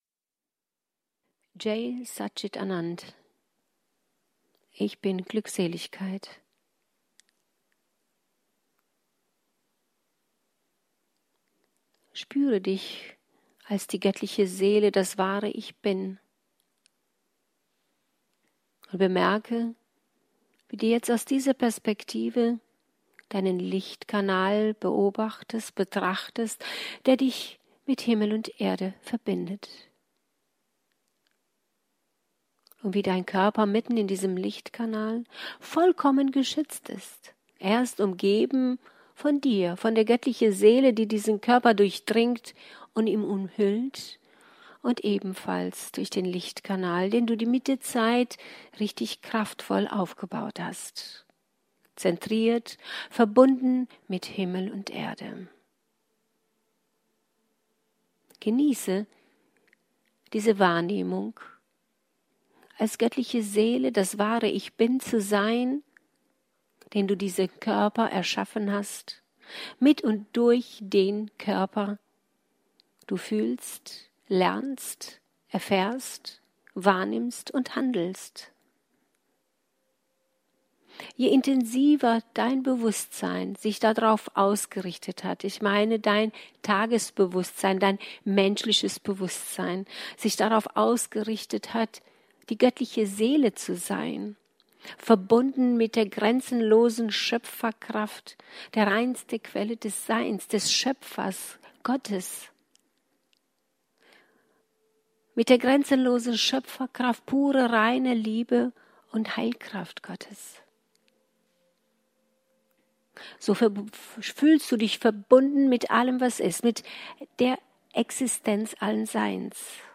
Zum Jahresende hatten wir zum Channeling-Abend nochmals zahlreichen Besuch sehr hoher Lichtwesen.